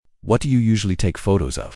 Part 1 (Introduction & Interview)